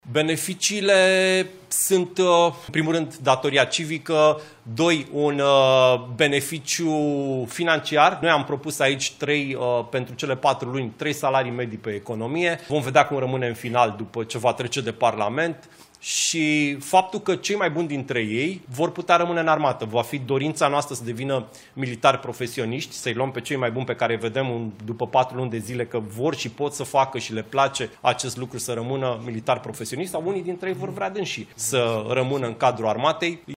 Ministrul Apărării, Ionuț Moșteanu: „Cei mai buni dintre ei vor putea rămâne în Armată”